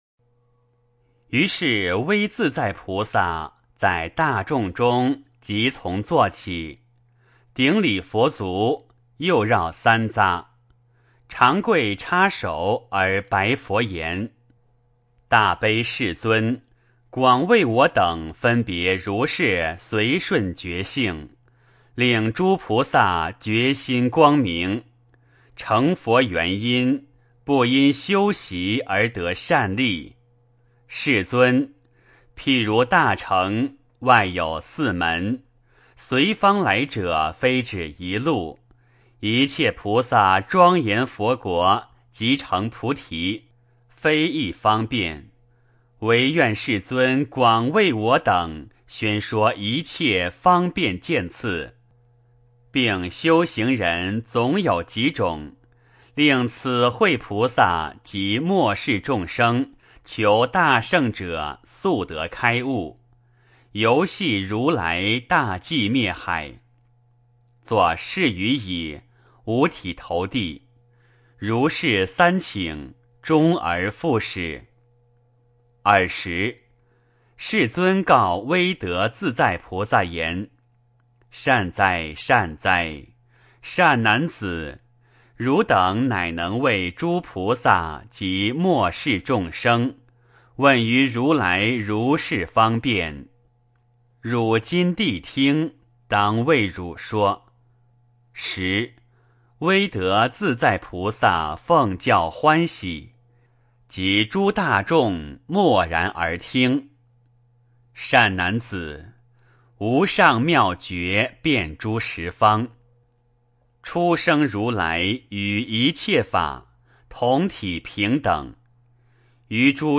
圆觉经-07威德自在菩萨 - 诵经 - 云佛论坛